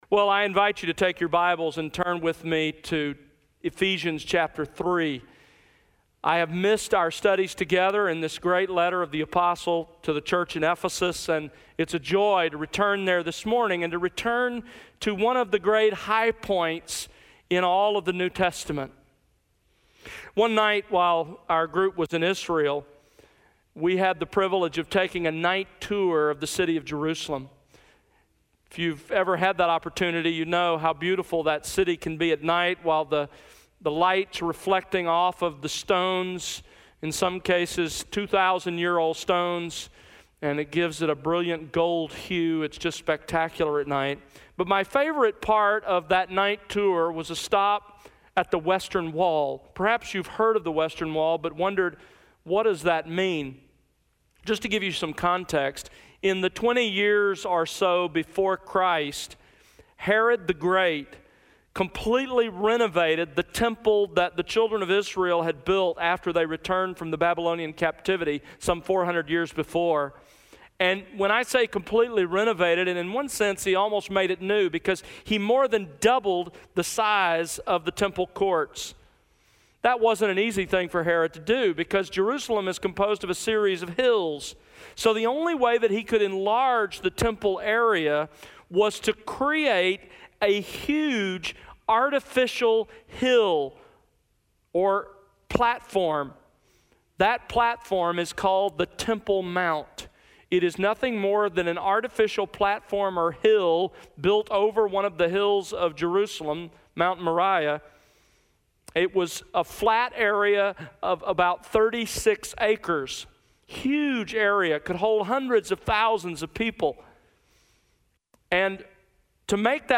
Sermons | The World Unleashed